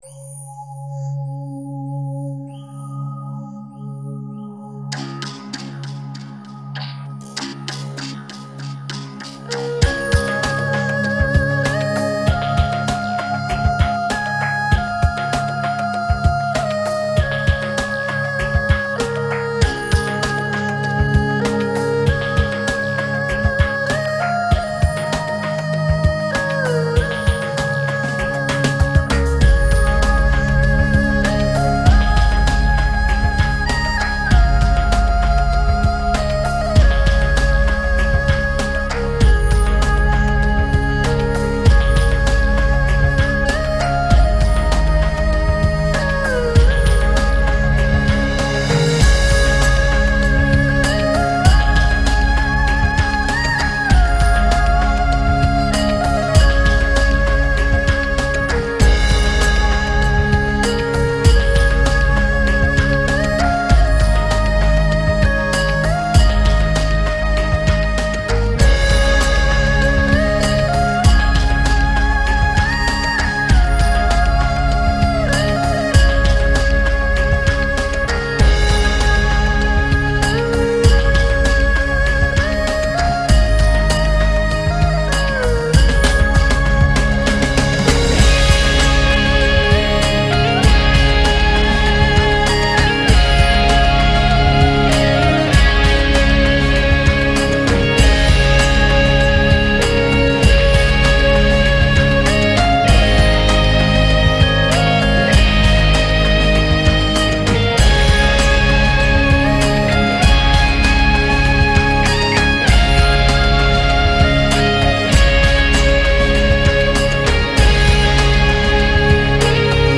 背景二胡音乐